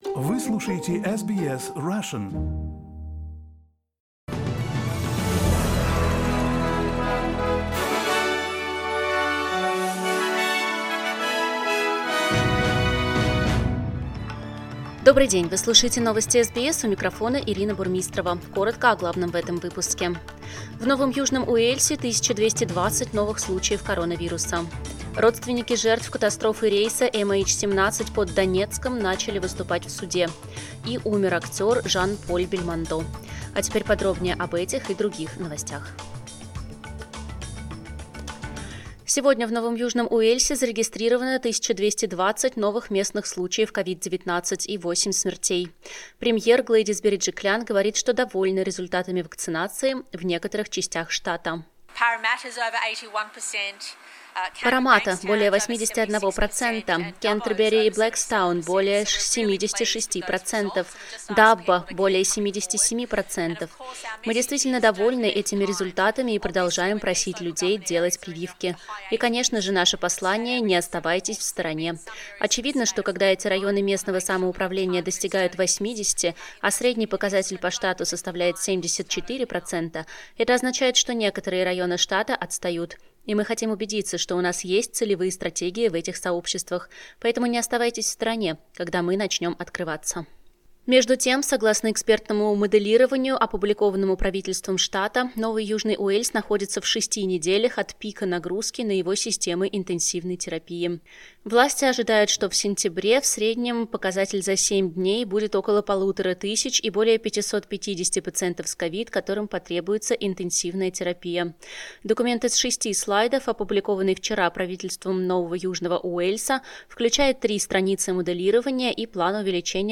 SBS news in Russian - 7.09